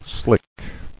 [slick]